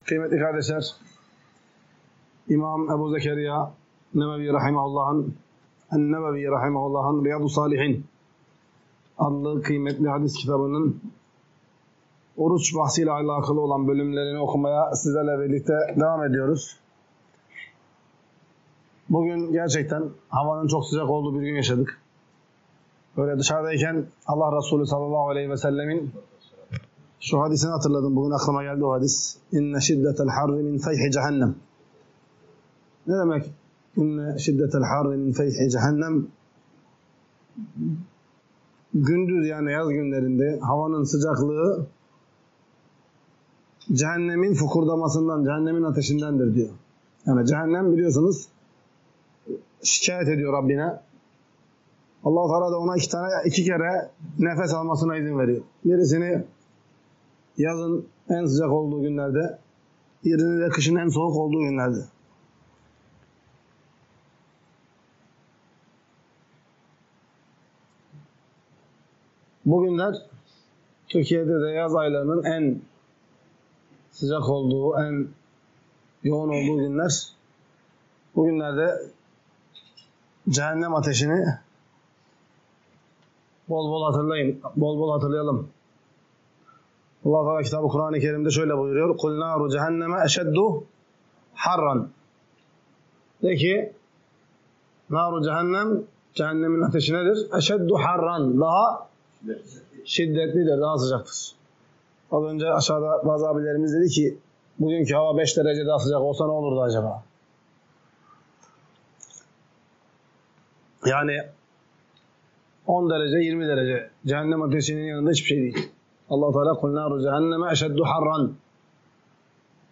Ders - 50. BÖLÜM | PAZARTESİ VE PERŞEMBE GÜNLERİ ORUÇ TUTMANIN MÜSTEHAB OLDUĞU